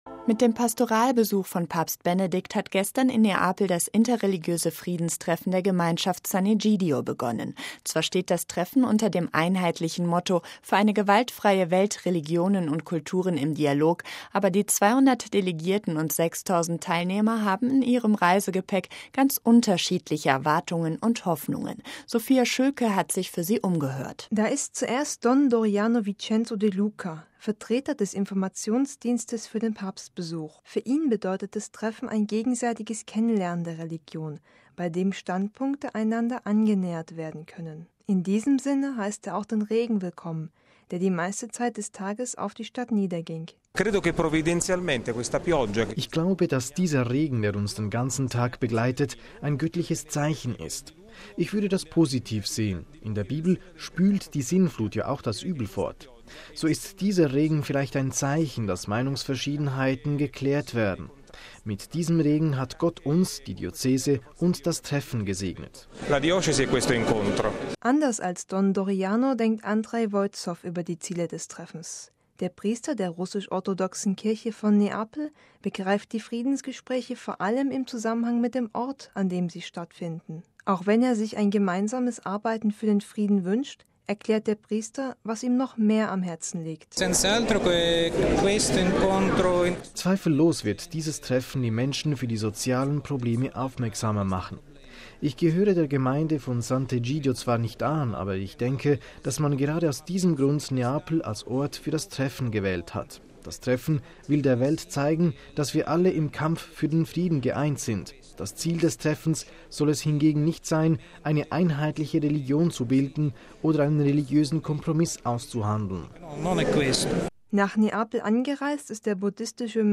Feature: Das Treffen der Religionen in Neapel.